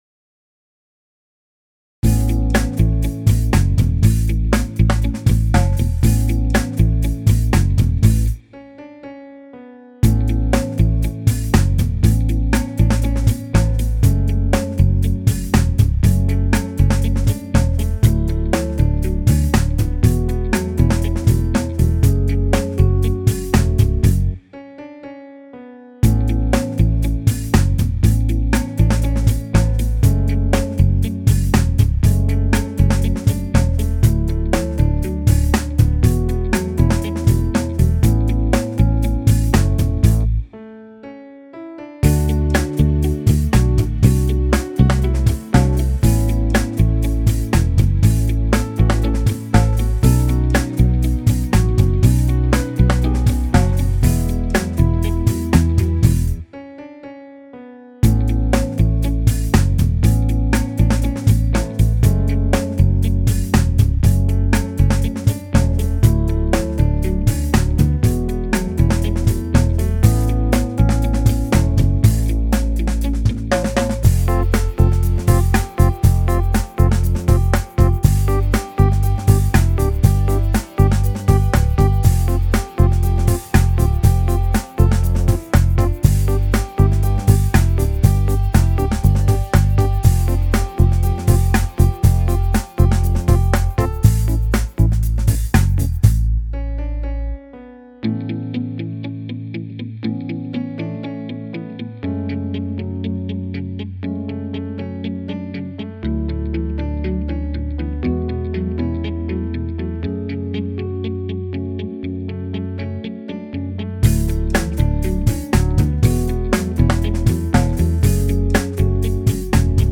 Accompagnement :